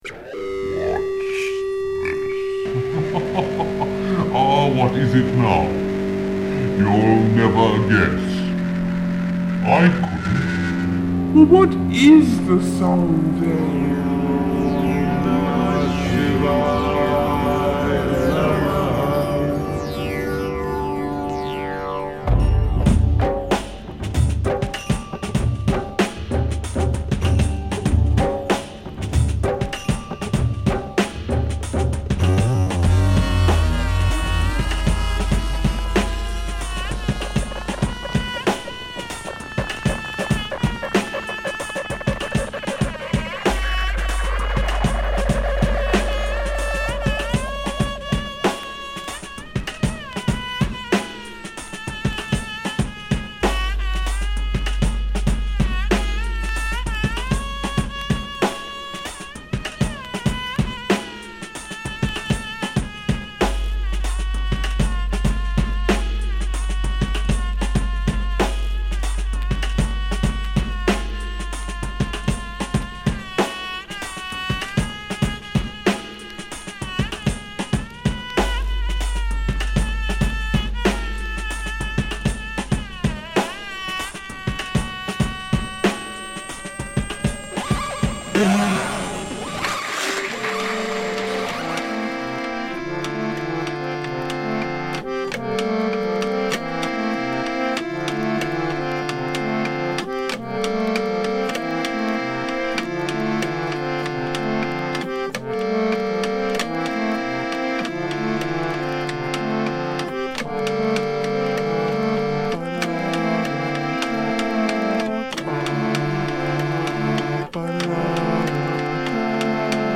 Beats Electronic Hip Hop Synth